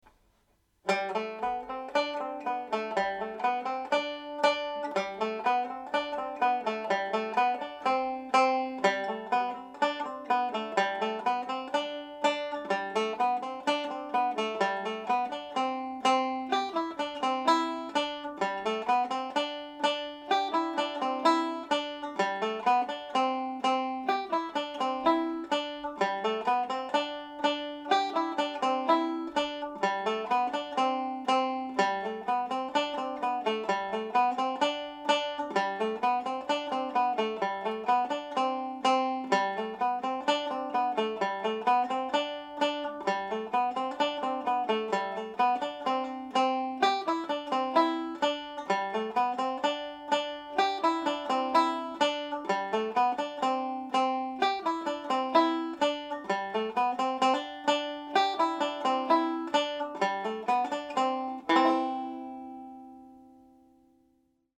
I’ve based this tutorial around learning the G major scale on the tenor banjo and mandolin.
Andy Reids Polka played faster
andy-reids-polka_fast.mp3